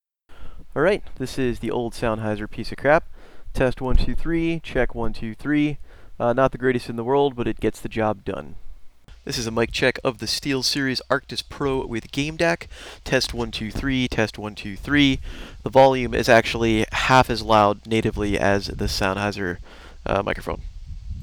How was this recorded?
Predictably, it also sounds great: It picks up better and is easily the clearer of the two.